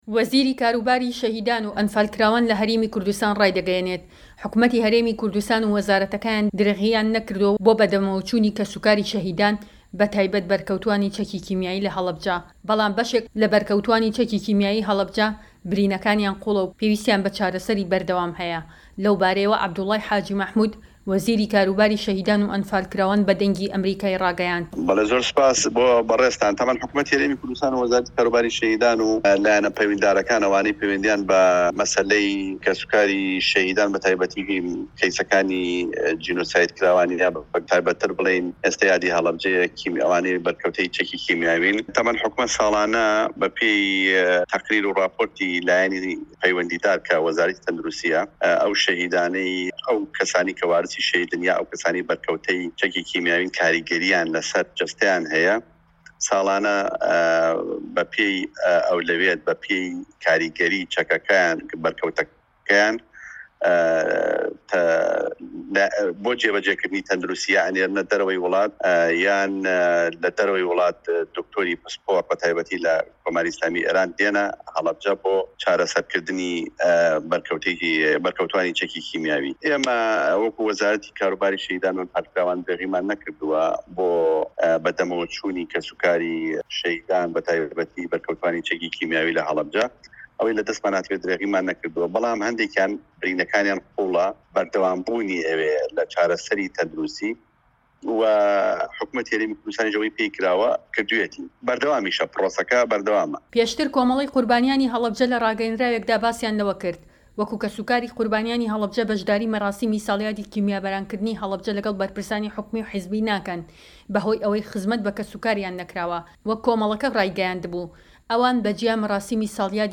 ڕاپۆرت لە بارەی ساڵیادی کیمیابارانکردنی هەڵەبجە-عەبدوڵای حاجی مەحمود